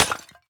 Minecraft Version Minecraft Version snapshot Latest Release | Latest Snapshot snapshot / assets / minecraft / sounds / block / decorated_pot / shatter4.ogg Compare With Compare With Latest Release | Latest Snapshot
shatter4.ogg